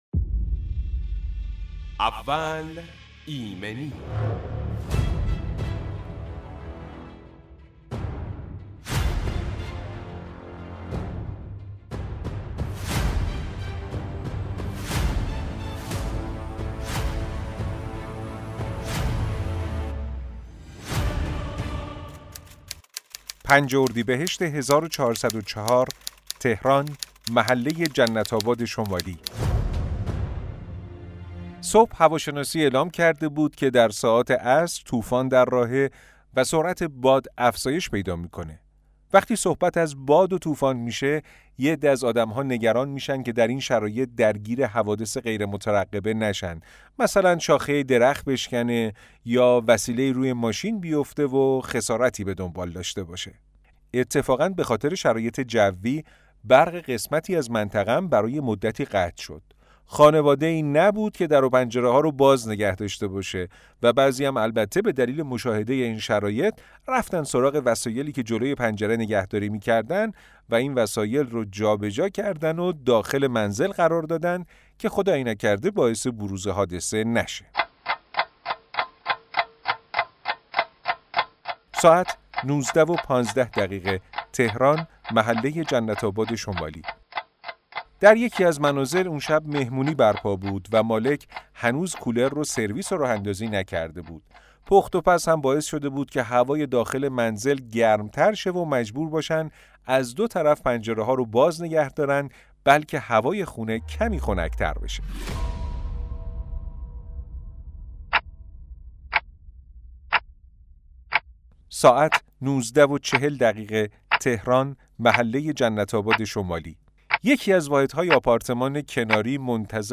برنامه اول ایمنی به مدت 30 دقیقه با حضور کارشناس متخصص آغاز و تجربیات مصداقی ایمنی صنعتی به صورت داستانی بیان می شود.